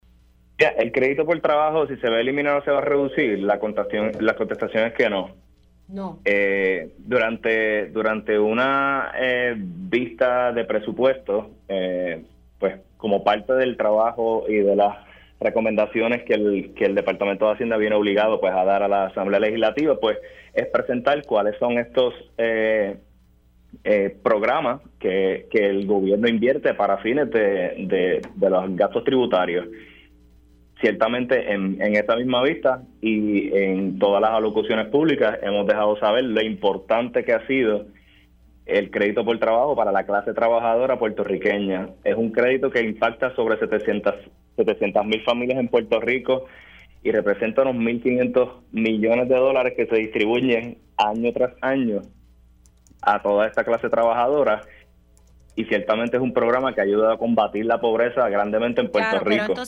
El Secretario de Hacienda, Ángel Pantojas aseguró en Pega’os en la Mañana que no eliminará el Crédito por Trabajo.